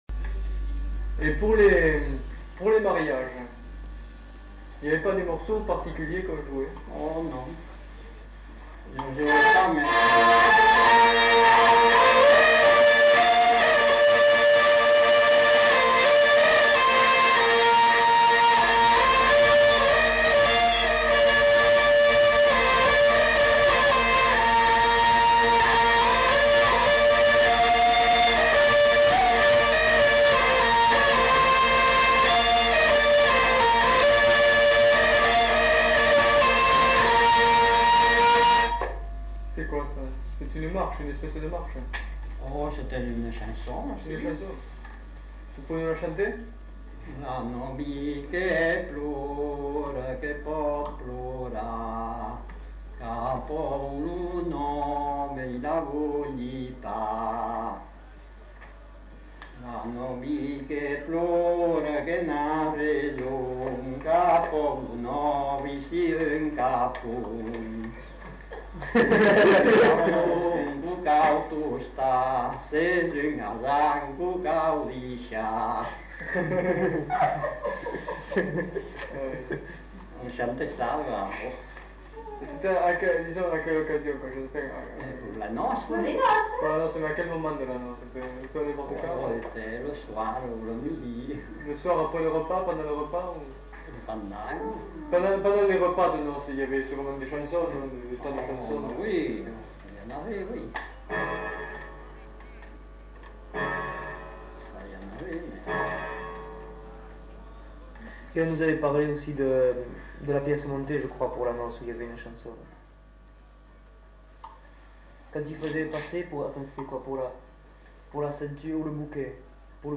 Aire culturelle : Petites-Landes
Genre : morceau instrumental
Instrument de musique : vielle à roue
Contextualisation de l'item : Pour la noce.
Notes consultables : Joue puis chante l'air.